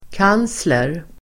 Uttal: [k'an:sler]